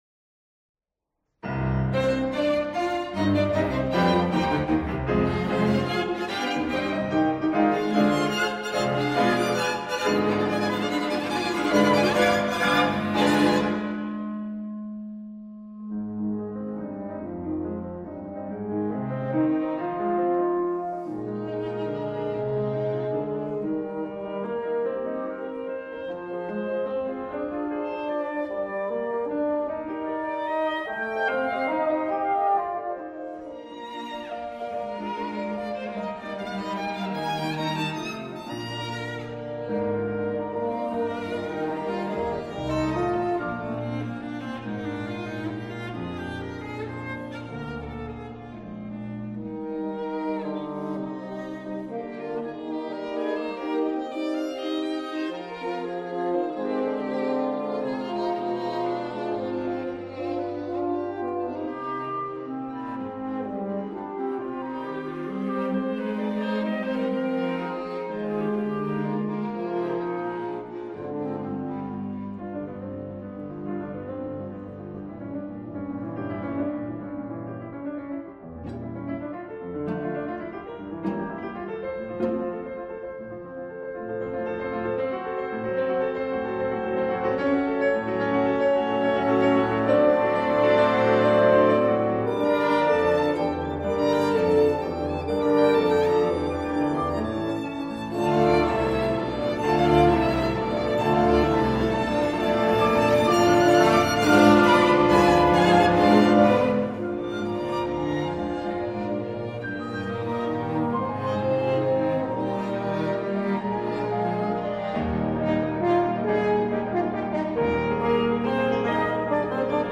Soundbite Movt 1
For 2 Violins, Viola, Cello, Clarinet, 2 Horns, Bassoon & Piano
When all of the instruments are engaged at one time, the sound truly approaches the orchestral.
However, this is almost immediately dissipated when only the winds and piano take over while the strings remain silent. For lengthy periods, there are episodes where each instrument, including the piano, is given long rests.